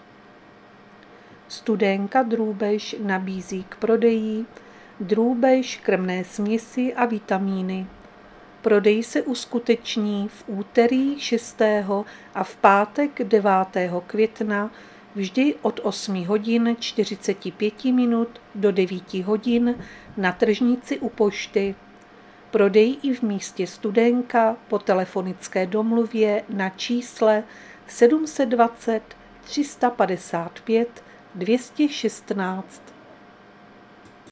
Záznam hlášení místního rozhlasu 5.5.2025